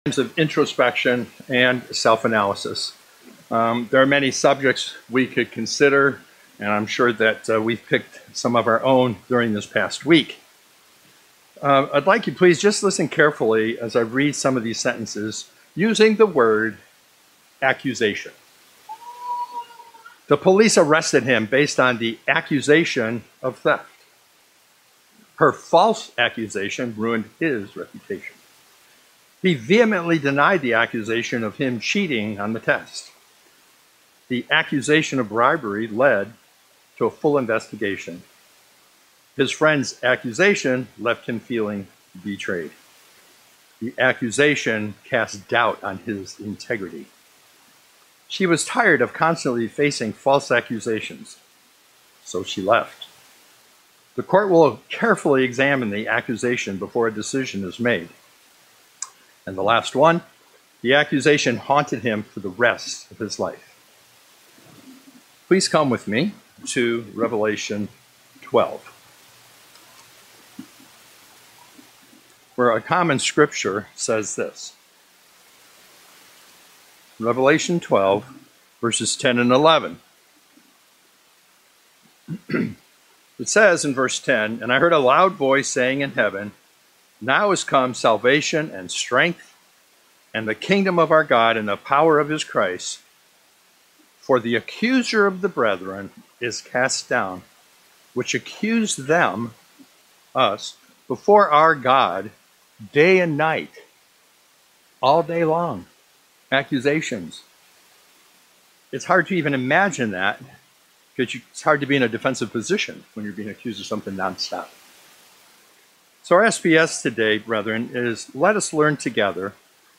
Sermons
Given in Tampa, FL